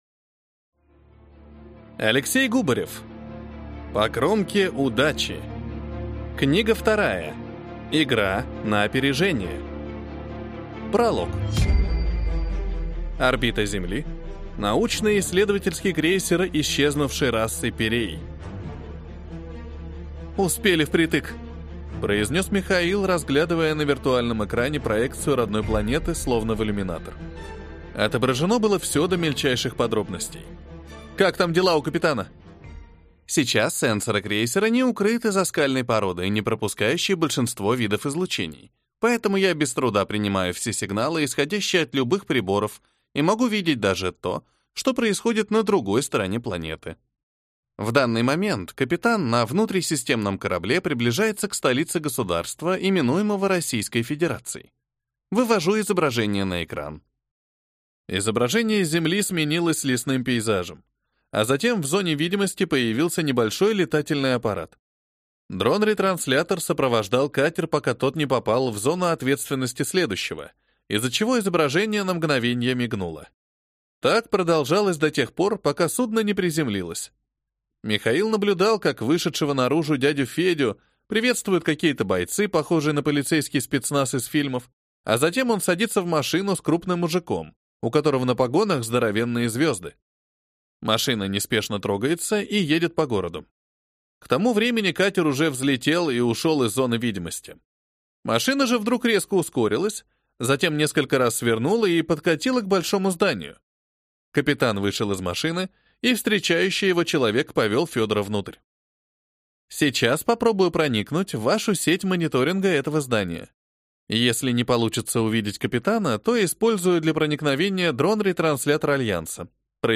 Аудиокнига По кромке удачи. Игра на опережение | Библиотека аудиокниг